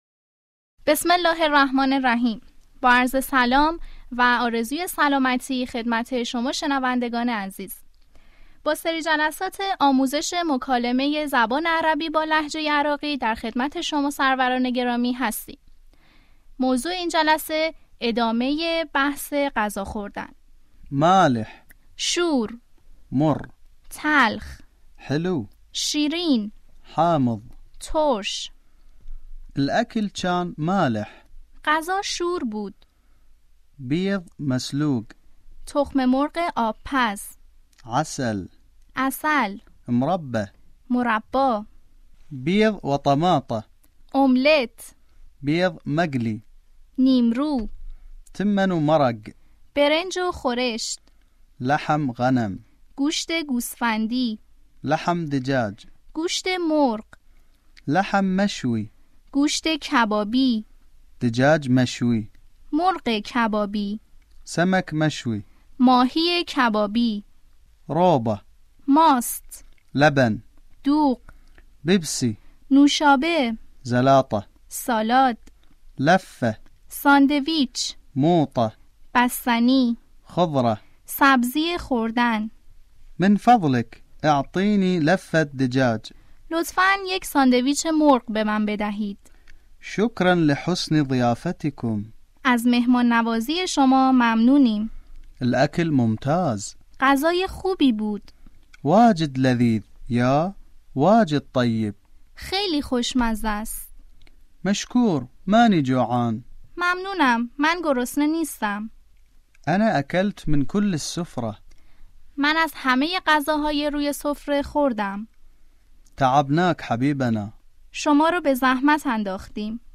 آموزش مکالمه عربی به لهجه عراقی